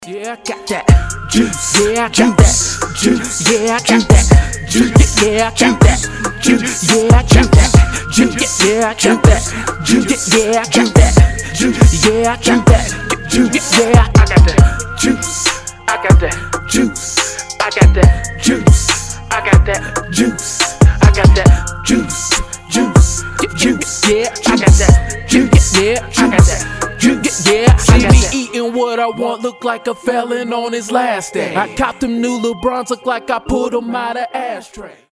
ритмичные
мужской вокал
Хип-хоп
качающие